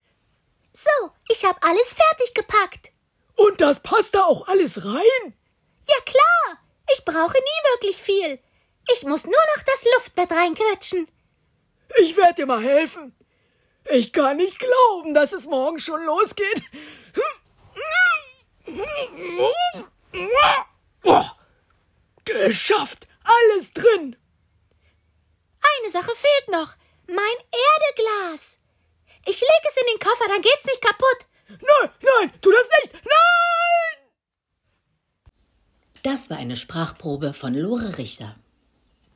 markant, plakativ, dunkel, sonor, souverän, sehr variabel, hell, fein, zart
Mittel minus (25-45)
Children's Voice (Kinderstimme), Comedy, Trick